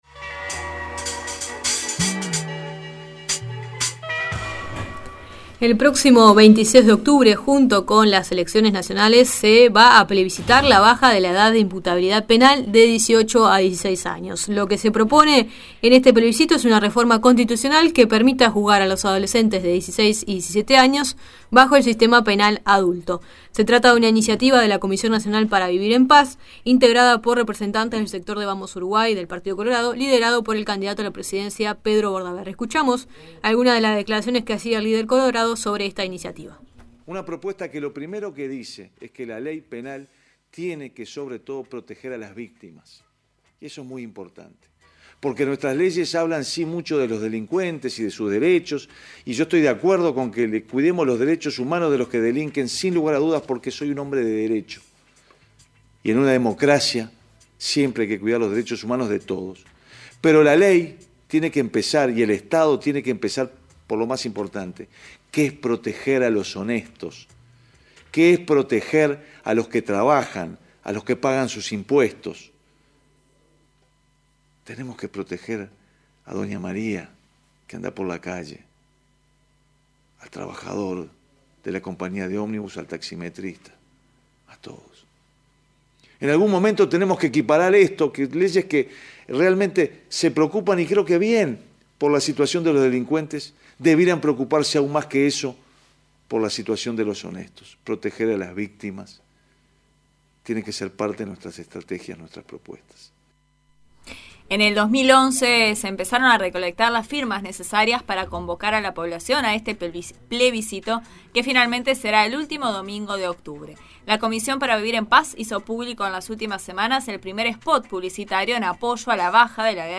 Para contribuir al debate público para la comprensión de este tema, La Nueva Mañana realizó un informe y conversó con